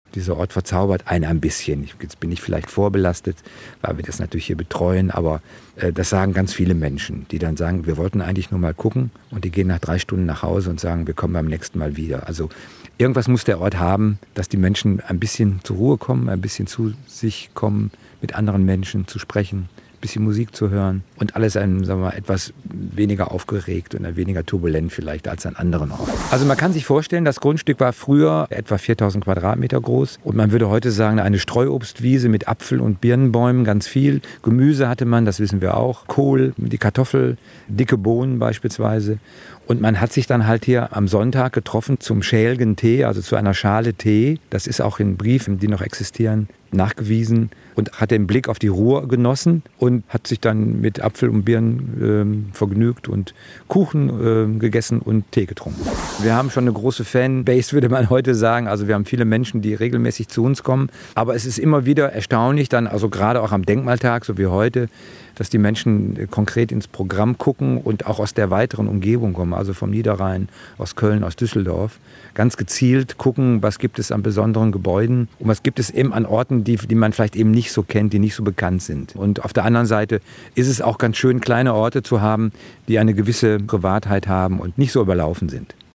Ein Interview in entspannter Atmosphäre
Im Hintergrund plätscherte der historische Brunnen und nebenan reiften die Äpfel in dem etwas verwunschenen Garten des Hauses.